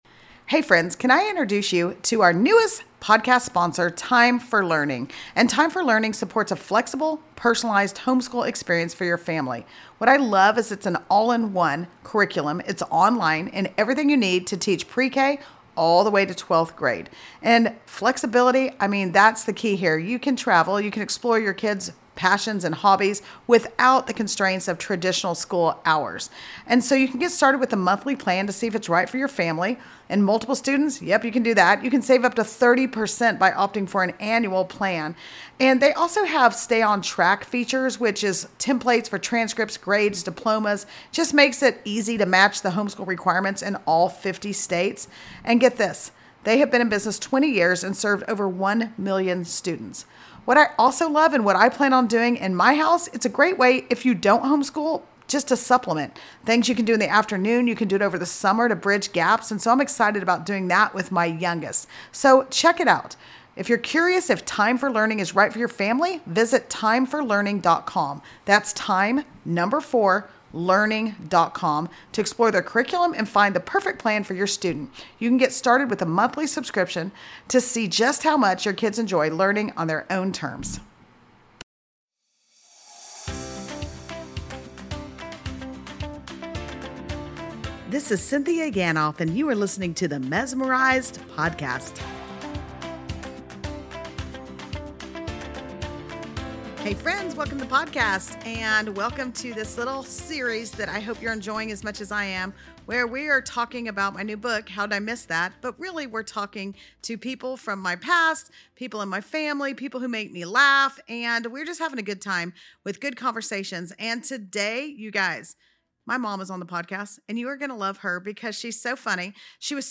What starts as playful banter turns into one of those meaningful episodes that makes you laugh and then quietly reevaluate your life.